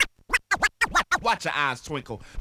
SCRATCHING  (7).wav